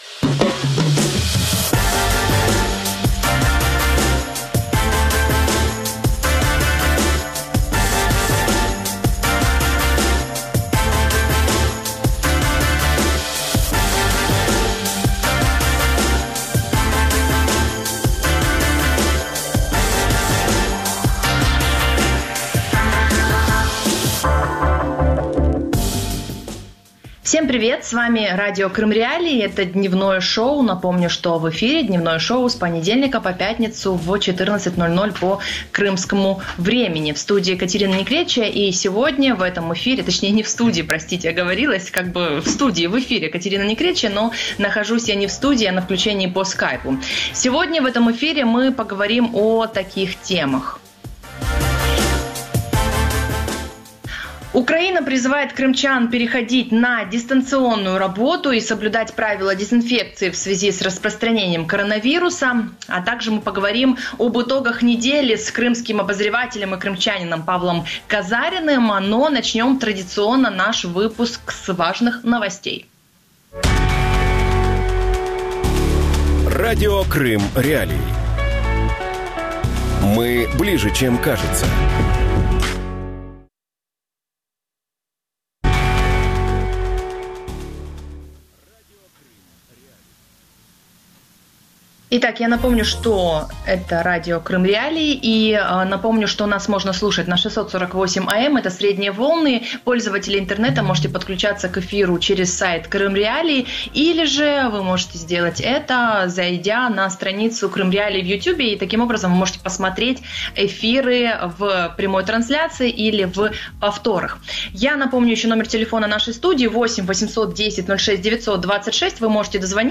Крым. Путин. Коронавирус | Дневное ток-шоу